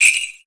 Index of /musicradar/essential-drumkit-samples/Shaken Perc Kit
Shaken Bells 02.wav